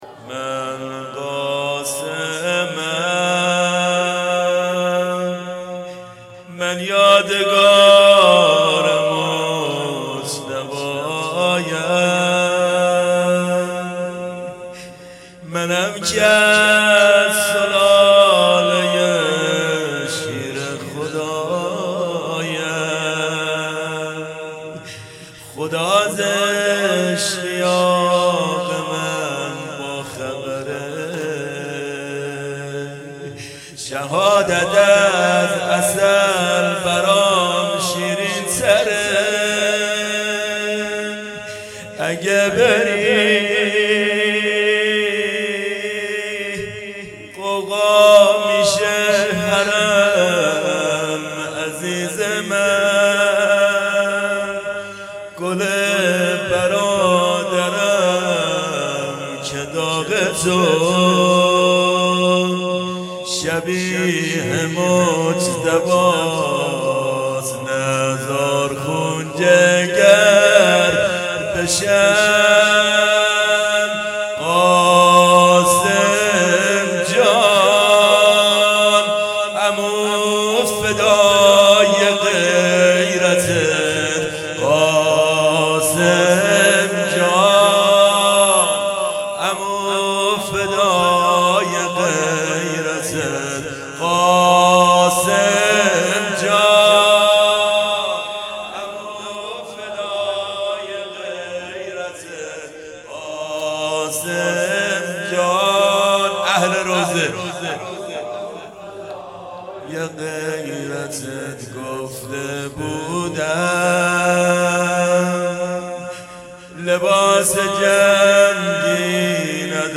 مکان:شهرک شهدا نبش پارک لاله هیئت علمدار سنقر